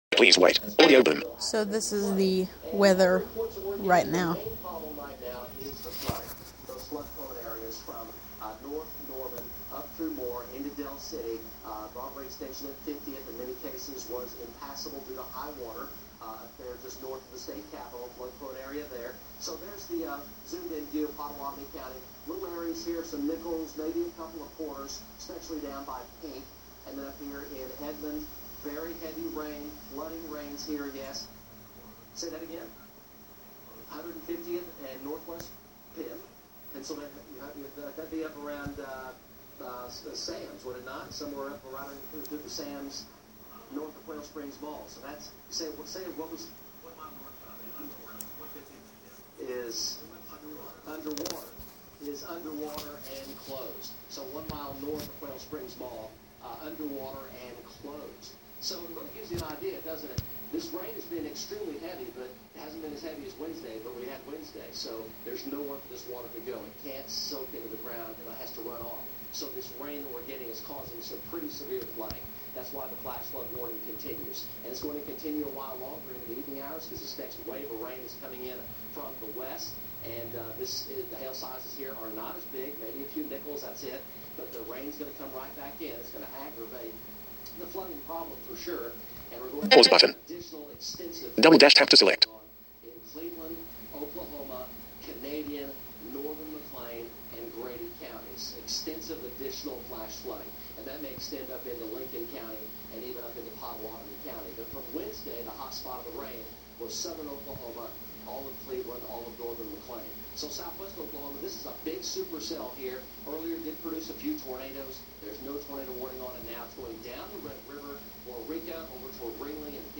severe thunderstorm in shawnee, oklahoma